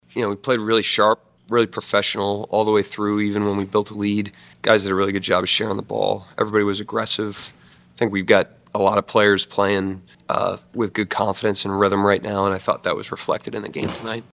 Head coach Mark Daignault says his club continues to play together.